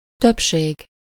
Ääntäminen
Synonyymit âge adulte Ääntäminen France: IPA: [ma.ʒɔ.ʁi.te] Haettu sana löytyi näillä lähdekielillä: ranska Käännös Ääninäyte 1. többség 2. nagykorúság 3. nagykorú Suku: f .